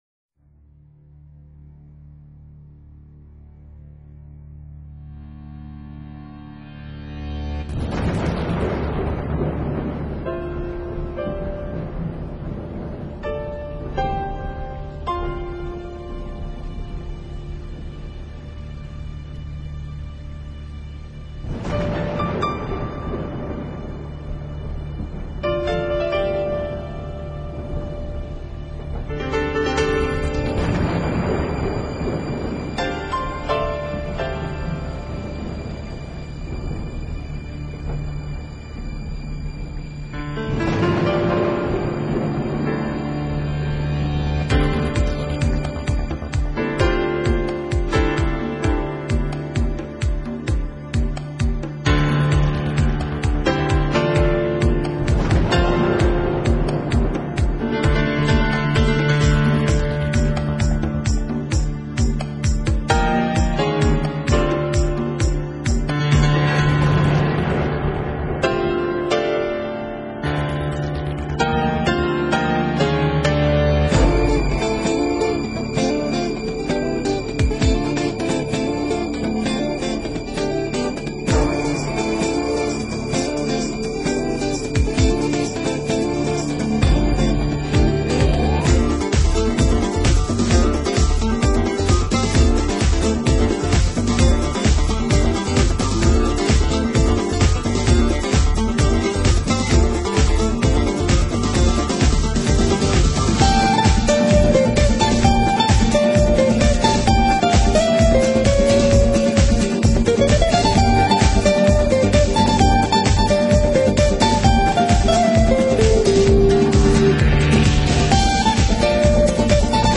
音乐类型：Jazz 爵士
音乐风格：NEWAGE，Smooth Jazz，Contemporary，Instrumental